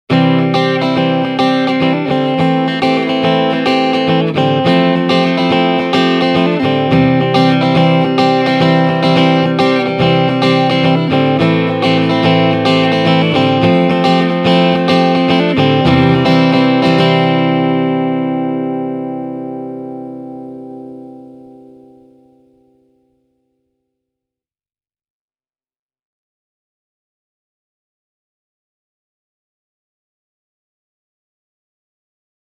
Äänitin seuraavat pätkät mikittämällä komboa:
magneettinen kaikuaukomikki (Mic/Line-kanava)
channel-2-mag-pu.mp3